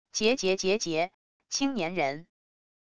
桀桀桀桀……青年人wav音频